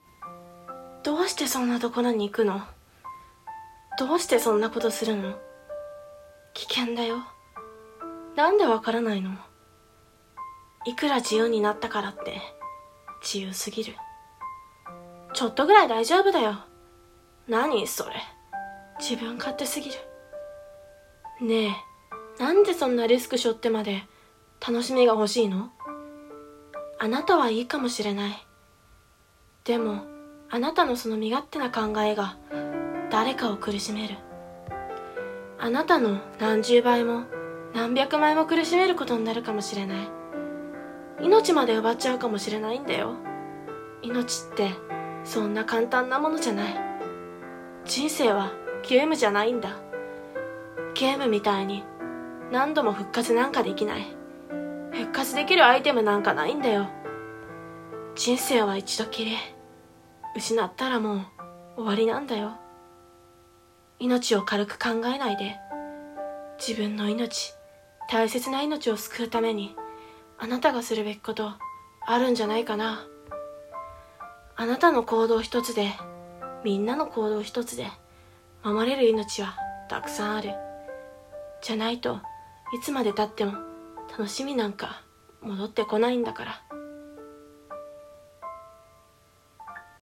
人生はゲームじゃない！【一人声劇】